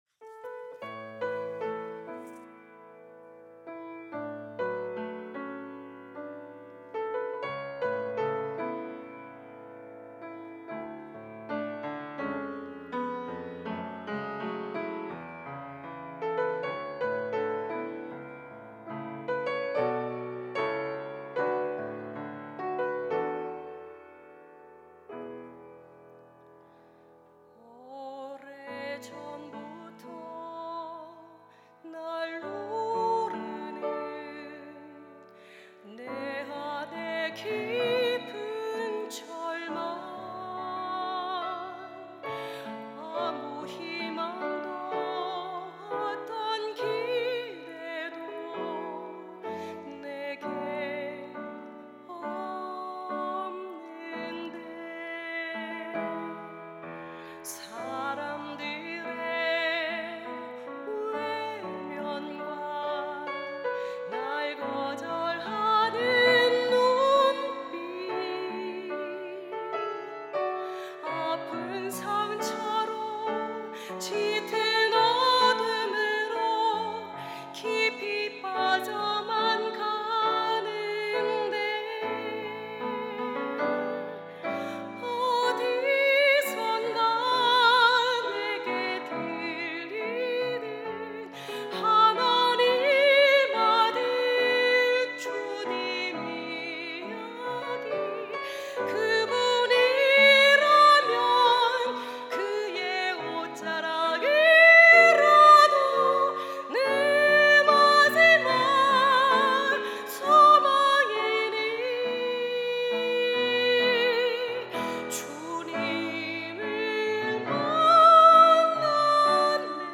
특송과 특주 - 옷자락에서 전해지는 사랑